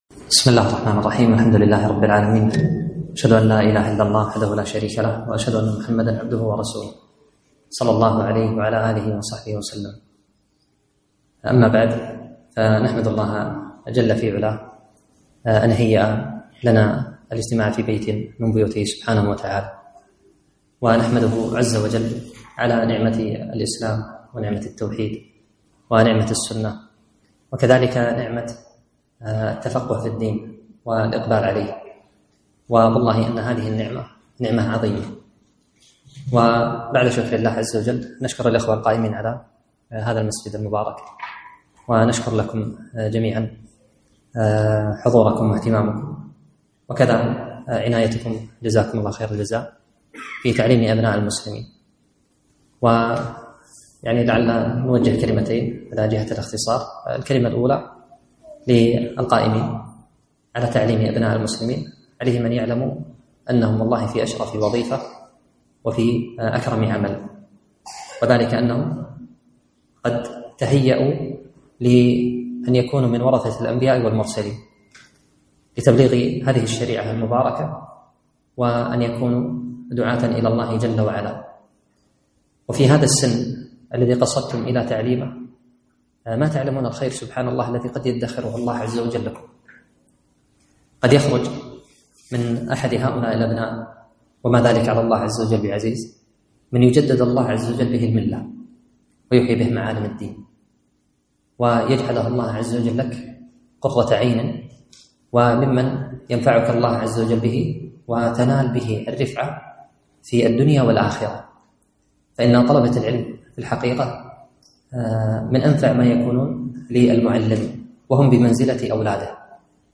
محاضرة - معوقات طالب العلم